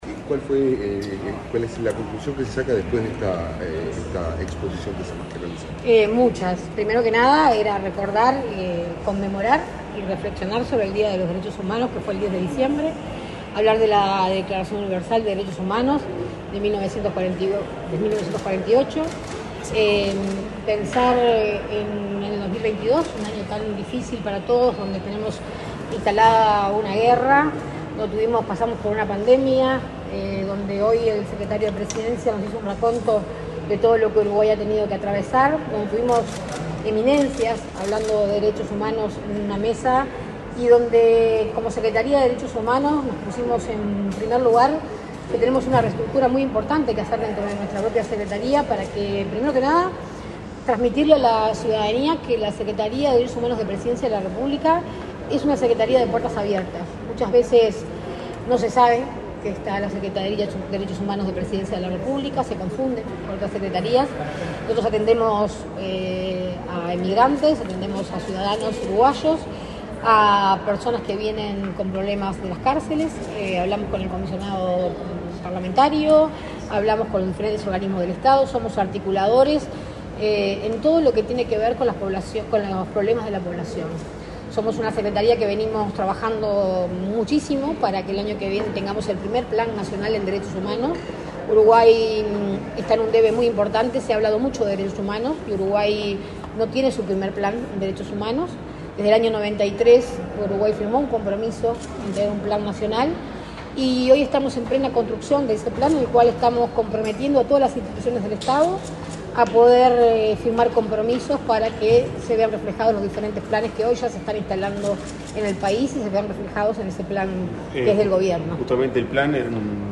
Declaraciones a la prensa de la secretaria de Derechos Humanos, Sandra Etcheverry
Tras el evento, la secretaria de Derechos Humanos, Sandra Etcheverry, realizó declaraciones a la prensa.